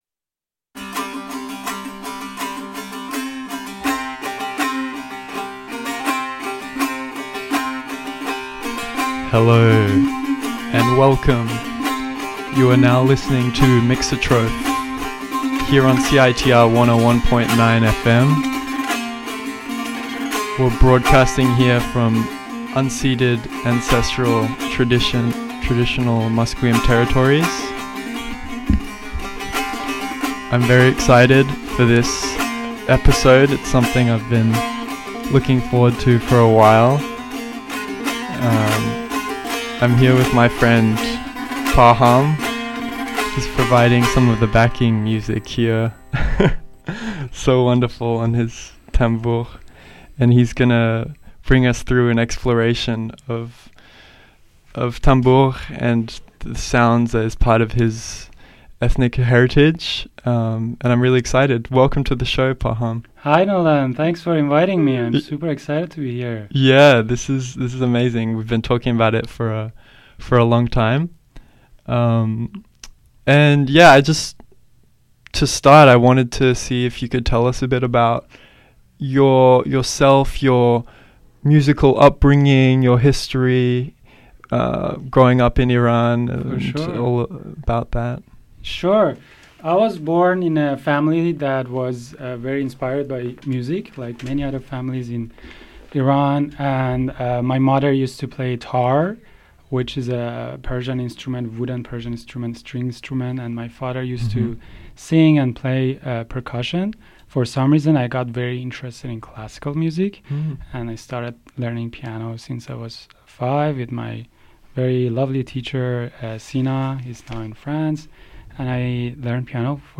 He shares many beautiful stories and even plays some tanbur himself for us.